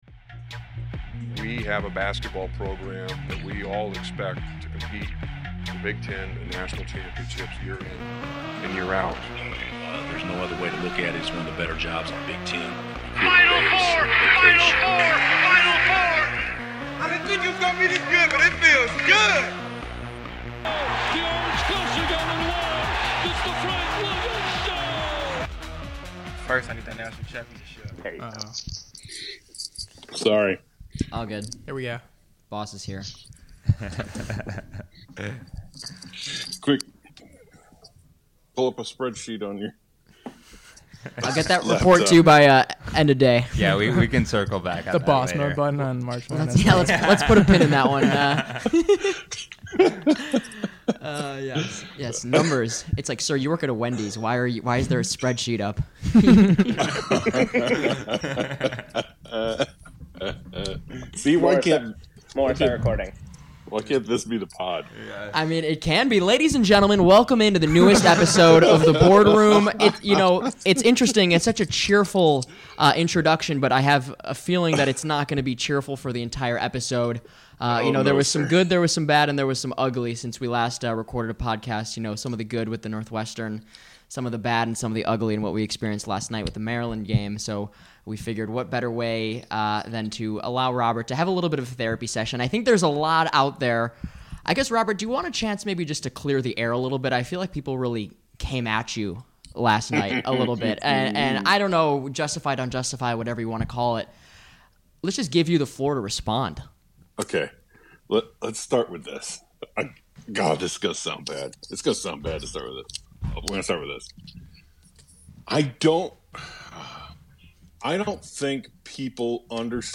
It’s raw, unedited, and will hopefully cheer you up too if you’re feeling a little scorched earth after the Maryland game.